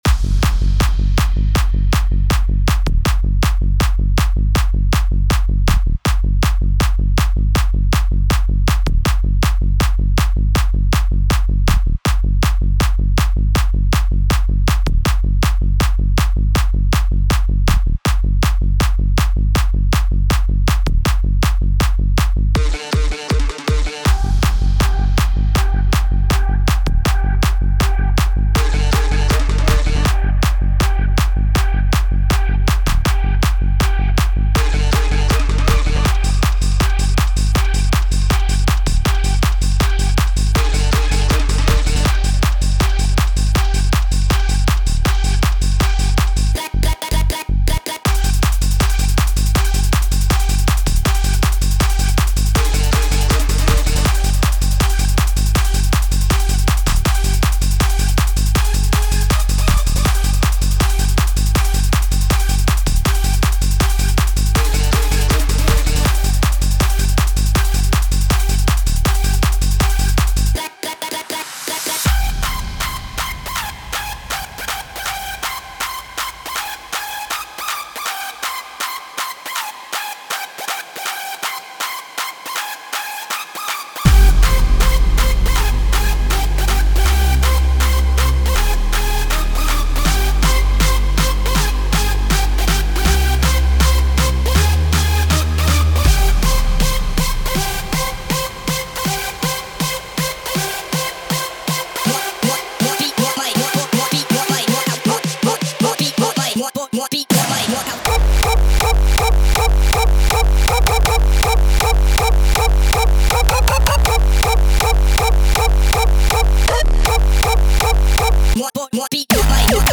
Gabber track voor de jeugd.mp3